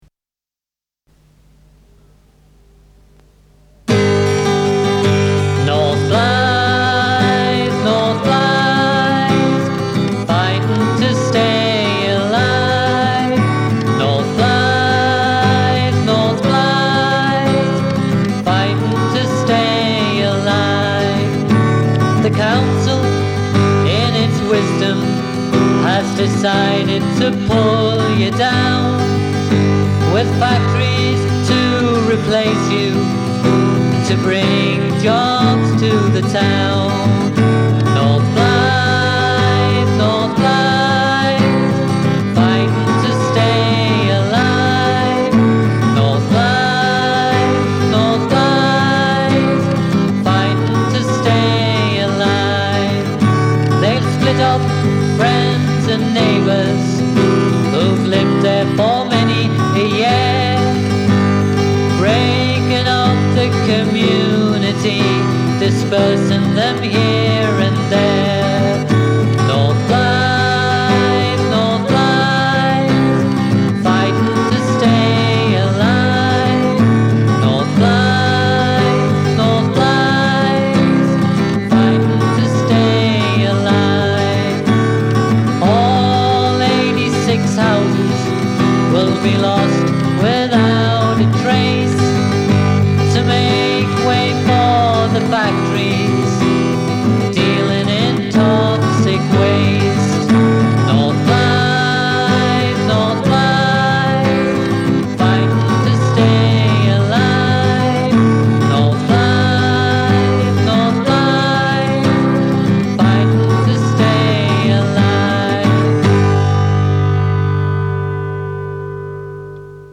Credits : Recorded at Project Studios, Newcastle Upon Tyne 1st August 1989.  Guitar & vocals
Flute